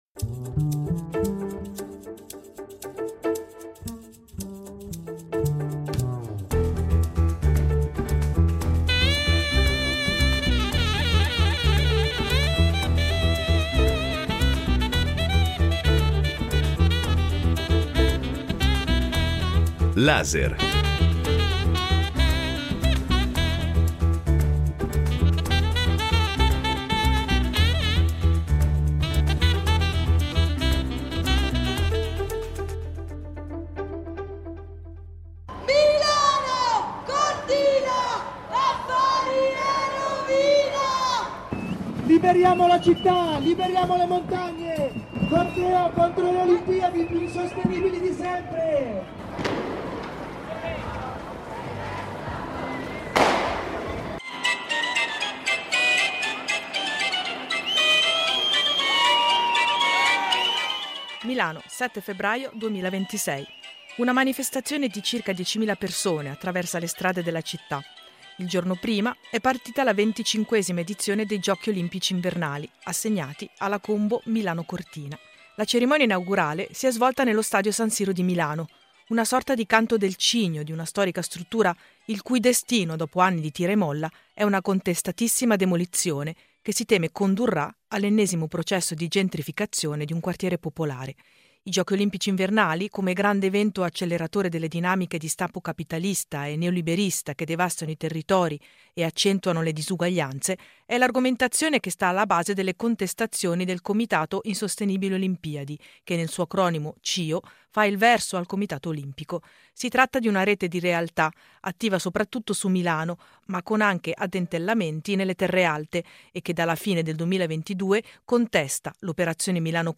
nel reportage sul campo realizzato poco prima dell’inizio dei Giochi invernali 2026